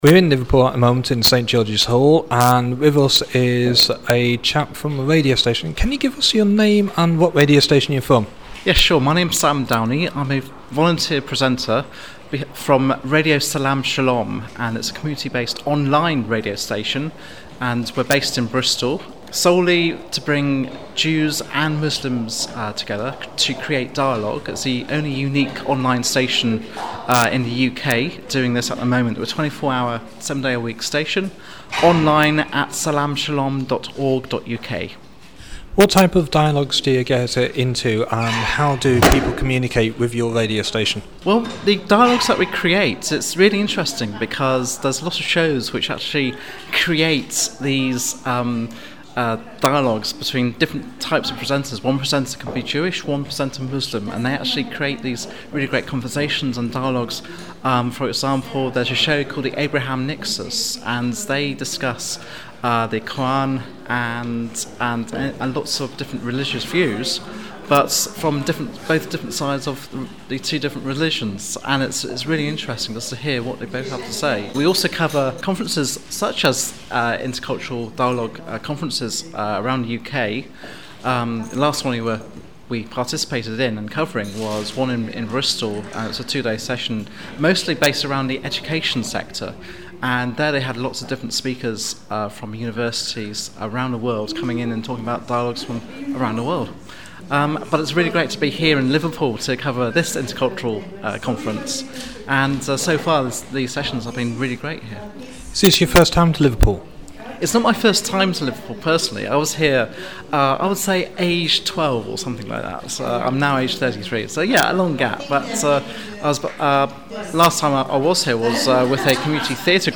Interview Format Format